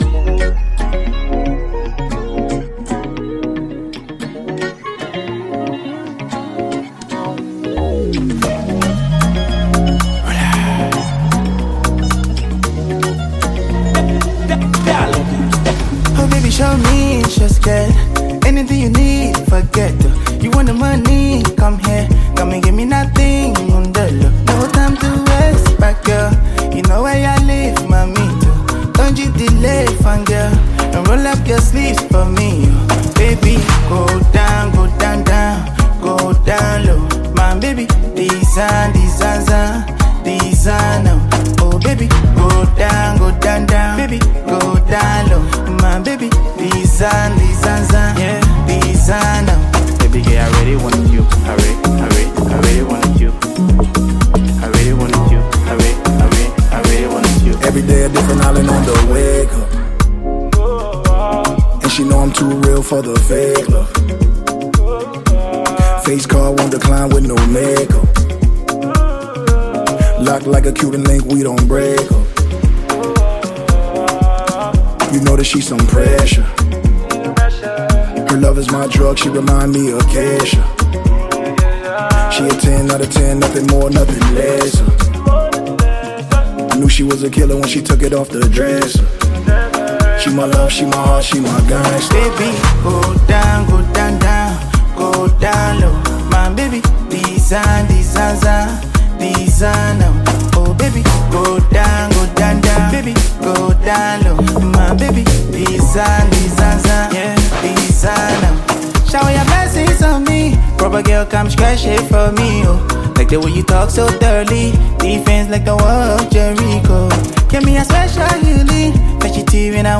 Categories: Afro-Beats,Afro-Pop
Tags: Afro-beatsAfro-PopHipcoLiberian music